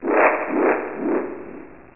Vyn Laugh